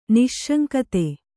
♪ niśśaŋkate